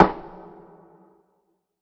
Perc [ Week 900 ].wav